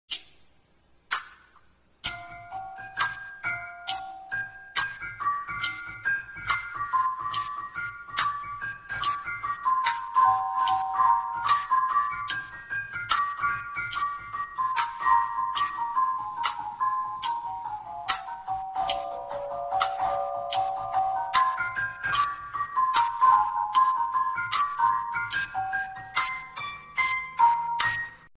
The new, smaller model was named khawng lek “small circle of gongs.”